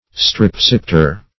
Search Result for " strepsipter" : The Collaborative International Dictionary of English v.0.48: Strepsipter \Strep*sip"ter\, Strepsipteran \Strep*sip"ter*an\, n. (Zool.)